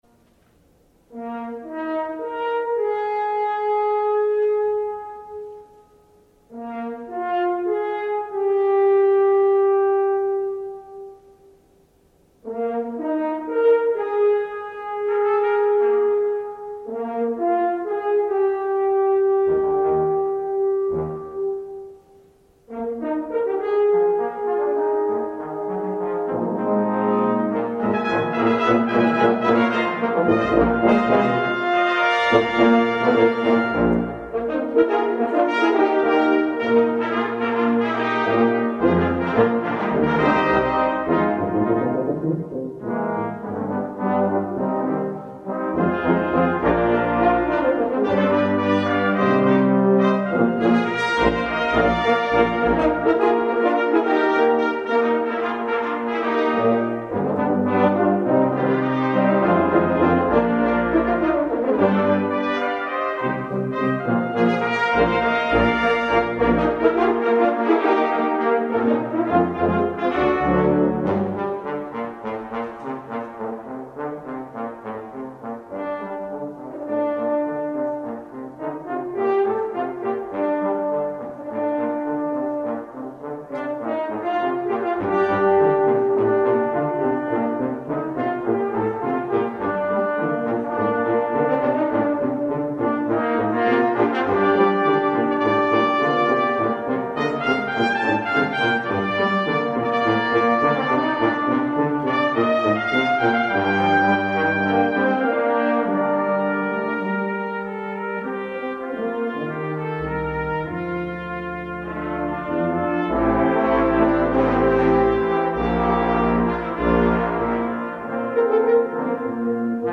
Voicing: Brass Ensemble